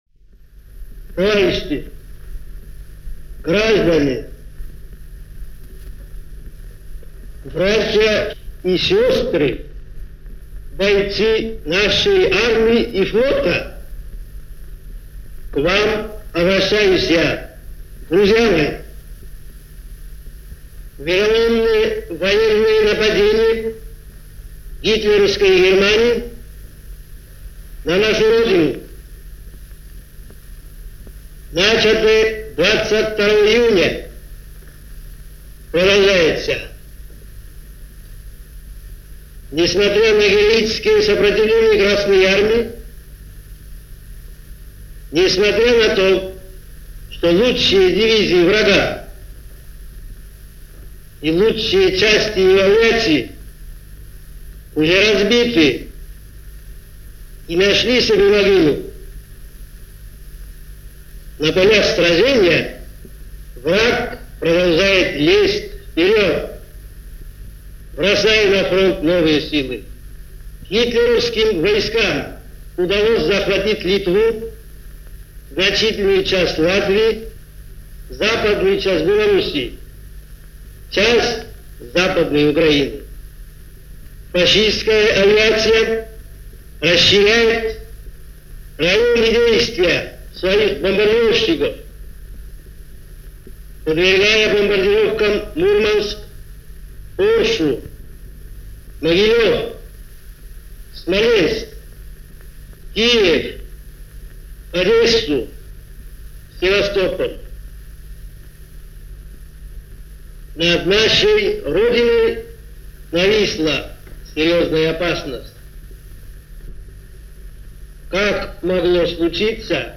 An Address By Joseph Stalin - to the Soviet People - Recorded on July 3, 1941 and broadcast over Radio Moscow.
Here is an address, in its entirety, given by Joseph Stalin to the Soviet People on July 3, 1941.